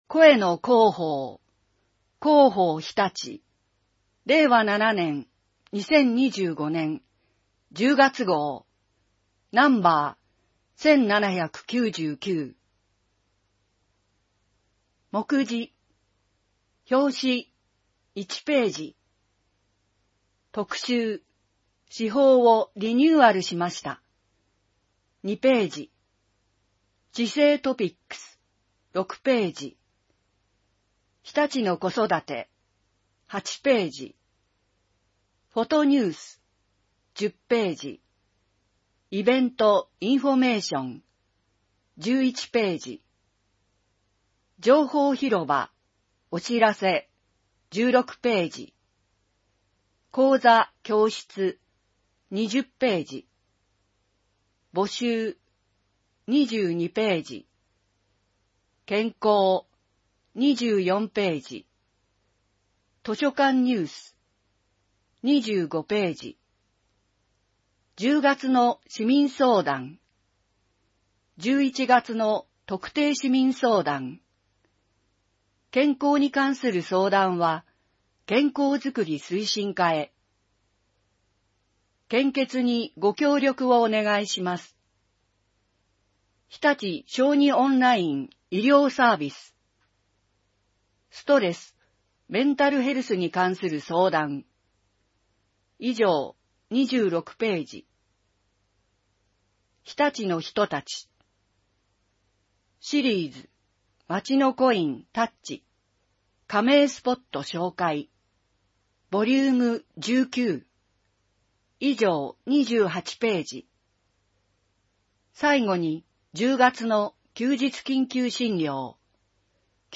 声の市報を読みあげます。